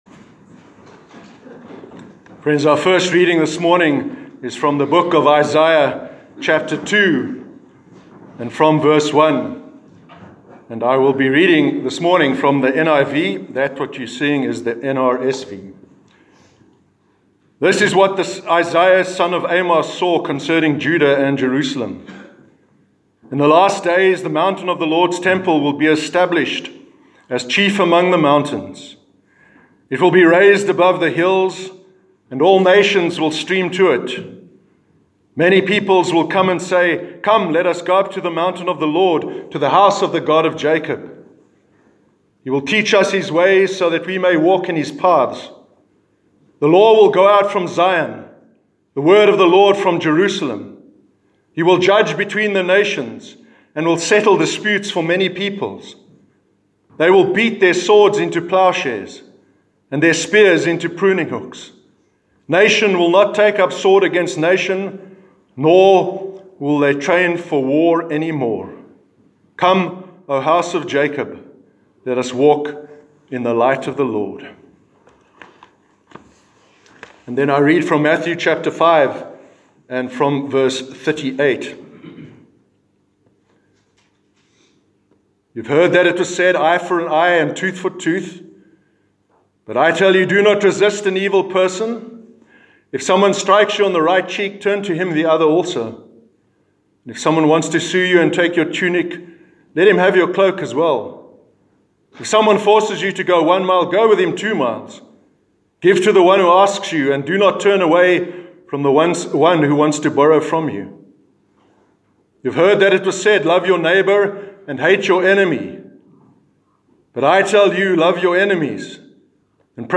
Remembrance Sunday Sermon- 10th November 2019